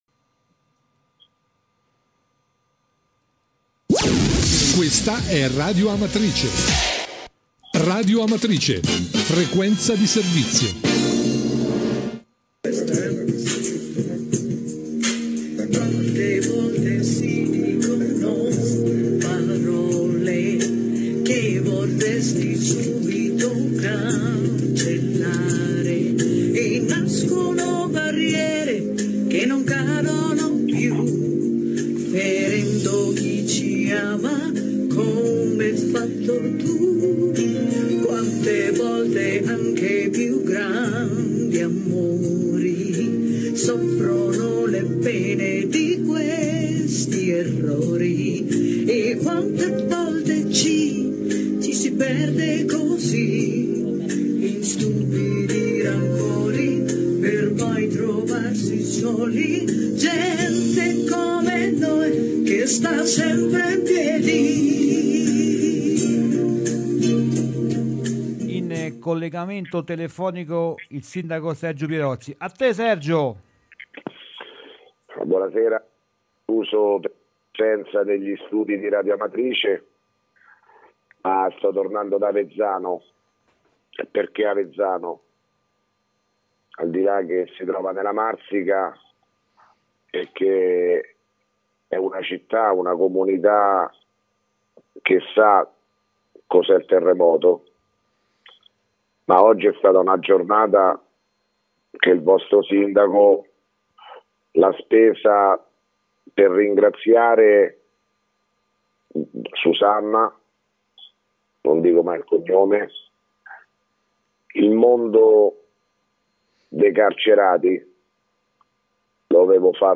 RADIO AMATRICE: MESSAGGIO DEL SINDACO PIROZZI (9 MAR 2017) - Amatrice